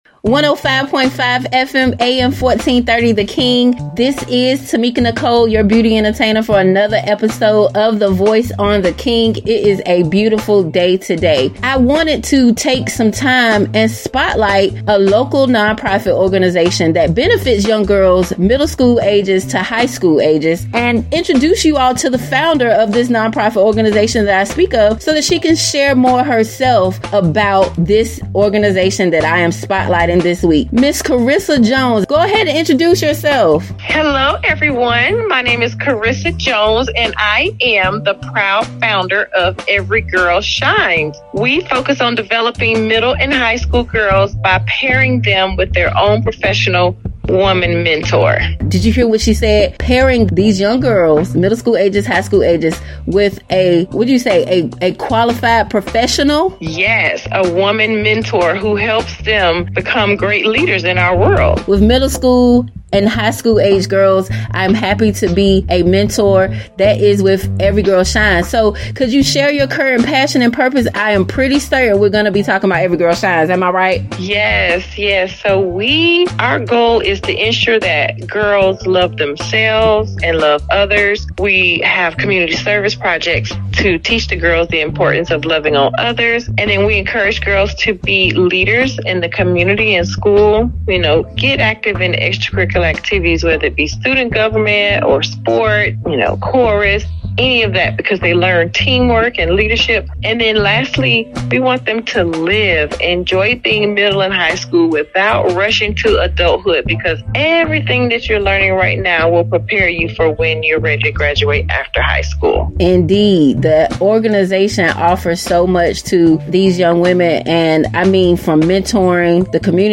The Voice Segment Show where local and national leaders share their stories with the world!
Heard on 105.5 FM/AM 1430 & AM 1010 The King